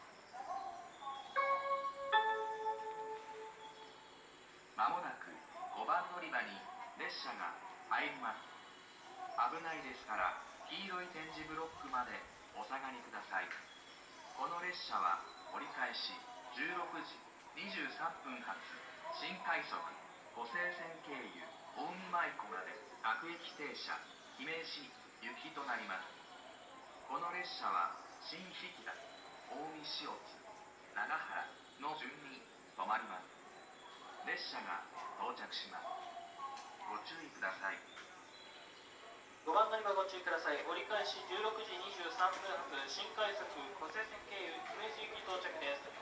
スピーカーも設置されており音量は大きめです。
５番のりばA：北陸本線
接近放送新快速　湖西線経由　姫路行き接近放送です。